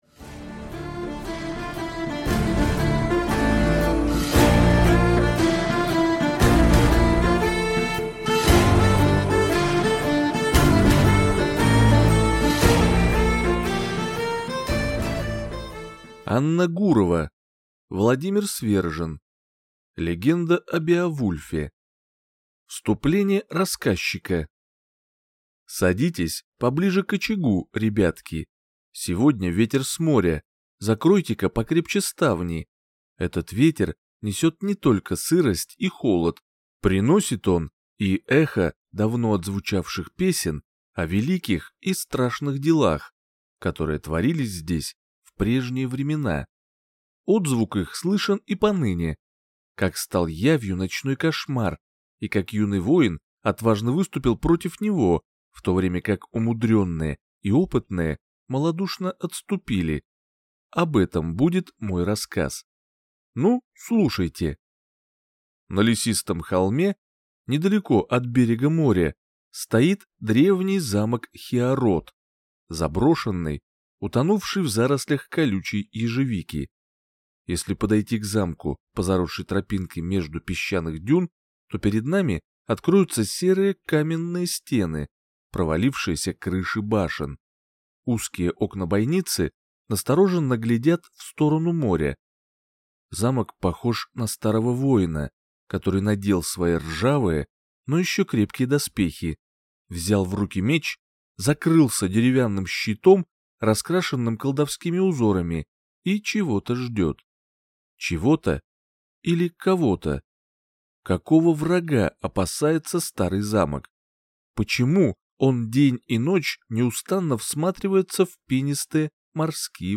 Аудиокнига Легенда о Беовульфе | Библиотека аудиокниг